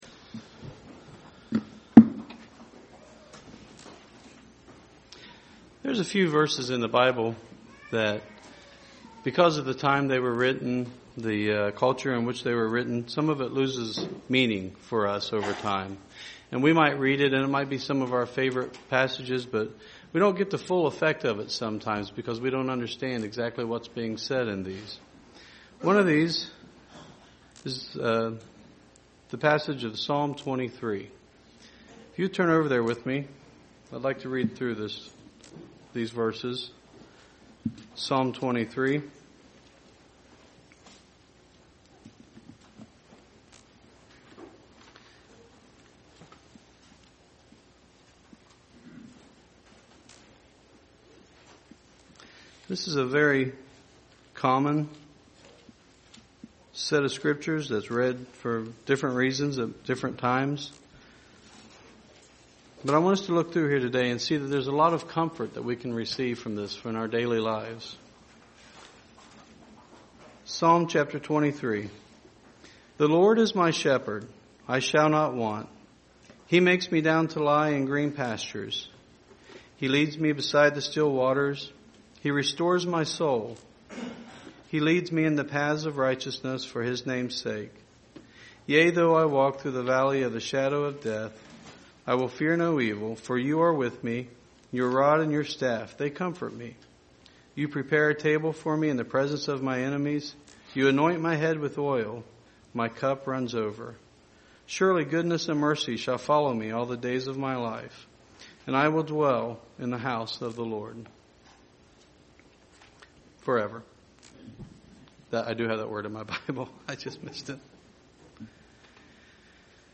Given in Dayton, OH
Dig a little deeper into the Psalm in this sermon to get a better grasp of what it is saying and how we are to apply it in our lives UCG Sermon Studying the bible?